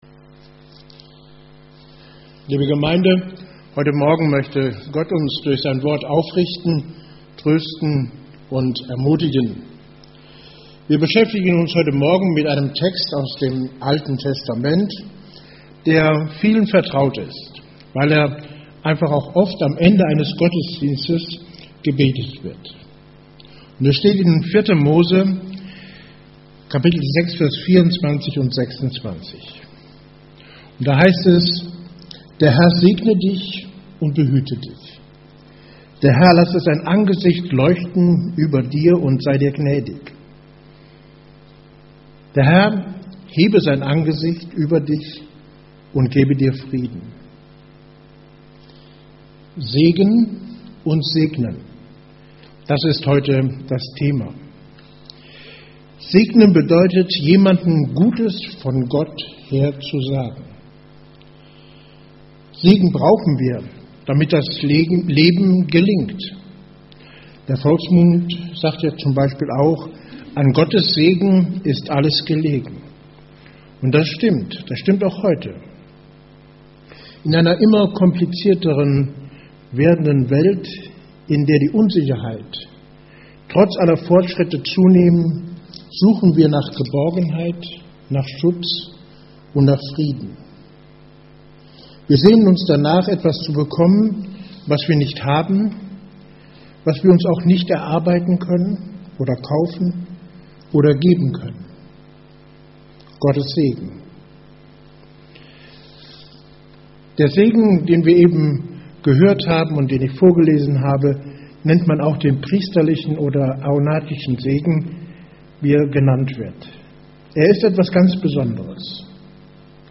Evangelisch-freikirchliche Gemeinde Andernach - Predigt anhören